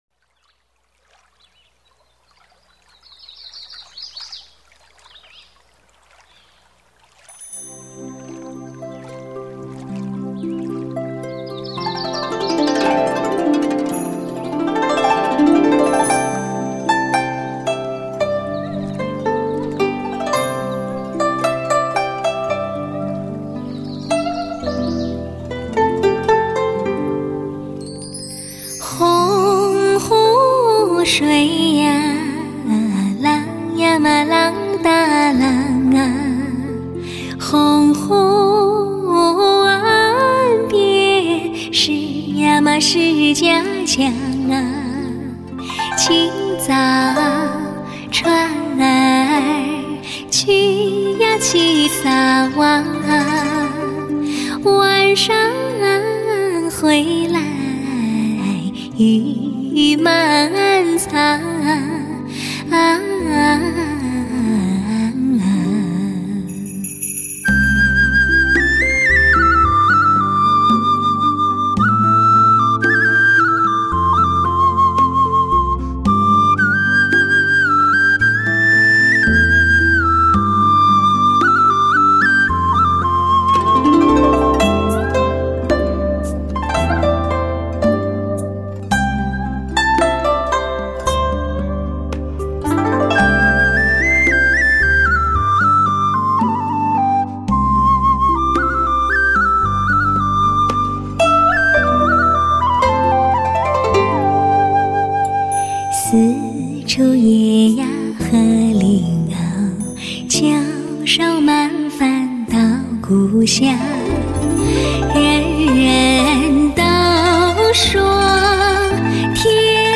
逼真绝伦的HI-FI音效，音乐质感细腻可触，传唱多年的经典旋律尤值今天细心回味！
难以忘怀的岁月留声，经年传唱不息，往日熟悉的歌声依旧动人心弦，原汁原味的怀旧曲风，指引心灵再次找回过去的音乐时光。